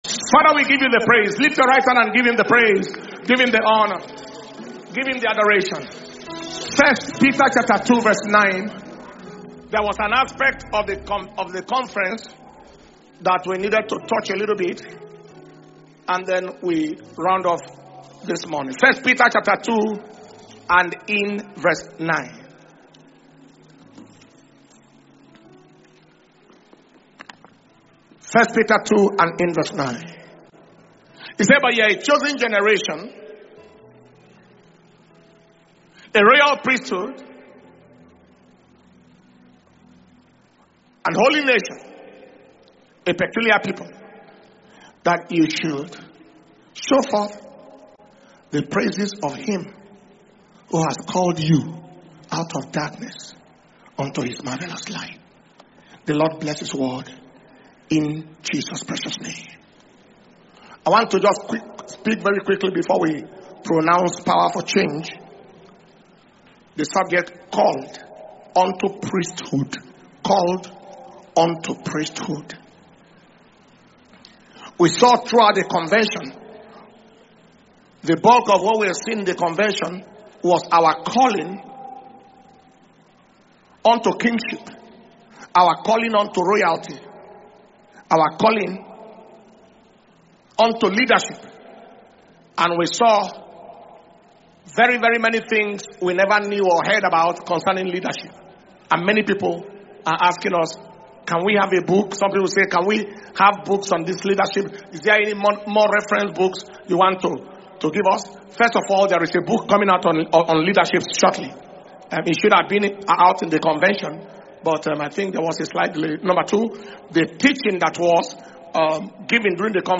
November 2025 Testimony And Thanksgiving Service - Sunday 30th November 2025